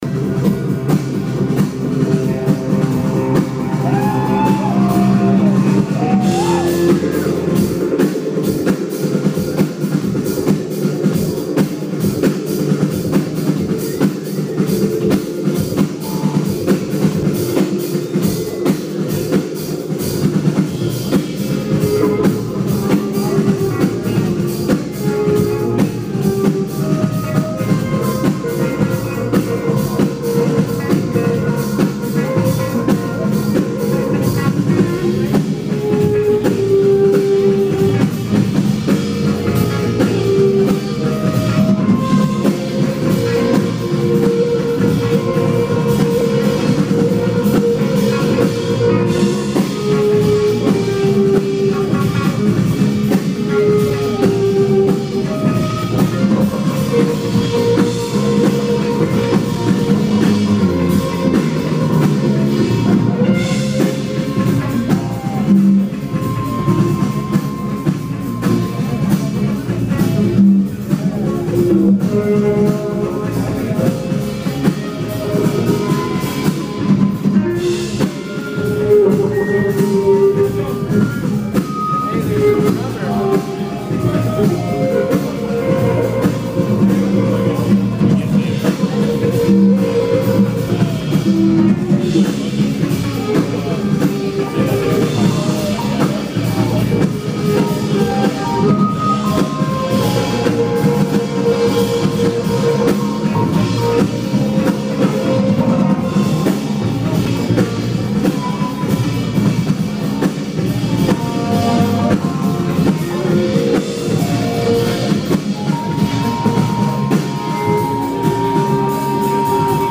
U Street Music Hall, Washington DC, 17th May 2016